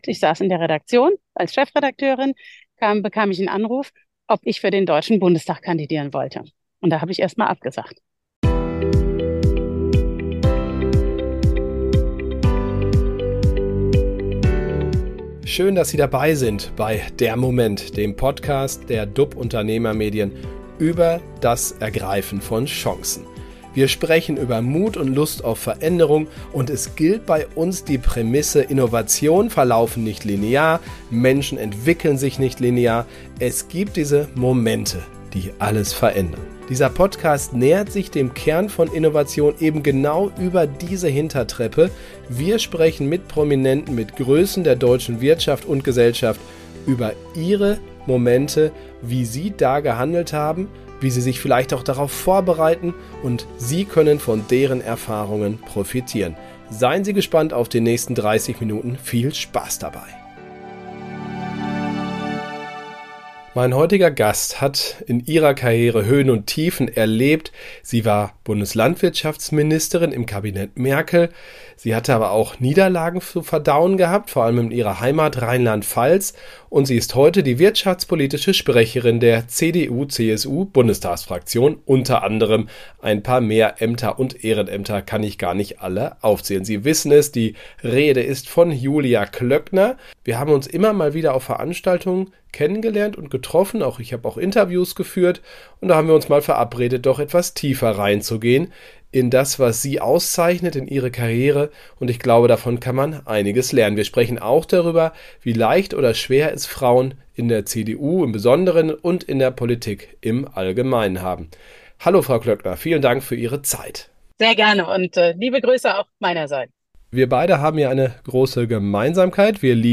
Die wirtschaftspolitische Sprecherin der CDU/CSU-Bundestagsfraktion spricht über Siege, Niederlagen und worauf es in der Politik ankommt.